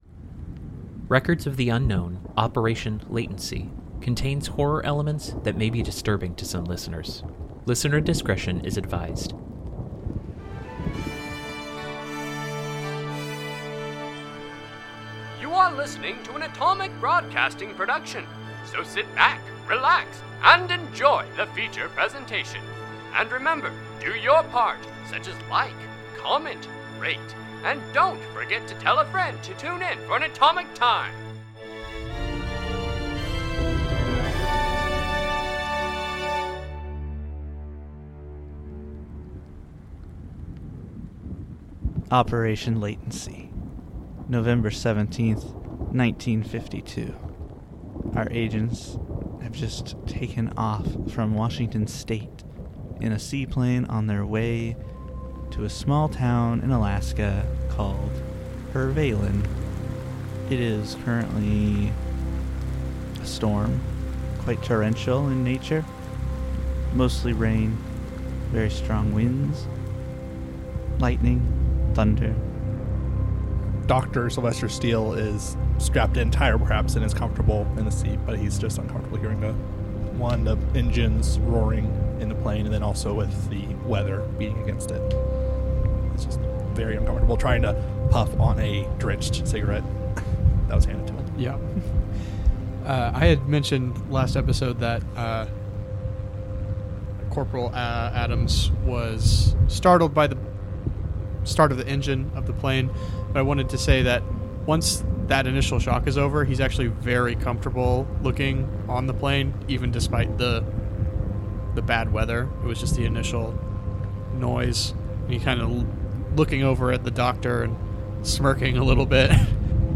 Records of the Unknown is an unscripted improvisational